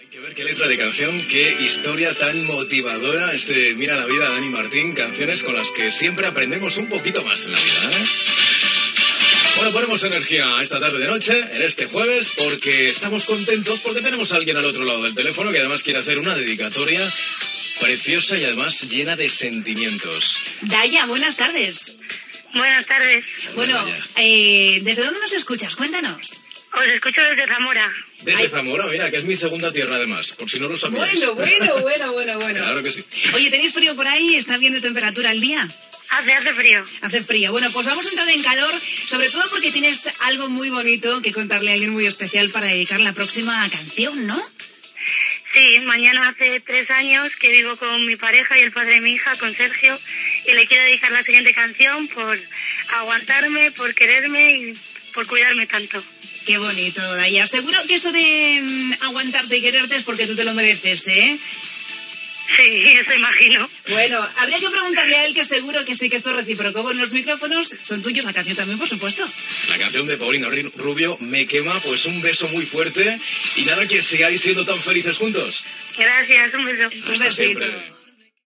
Tema musical, trucada d'una oïdora que dedica una cançó a la seva parella des de fa tres anys
Musical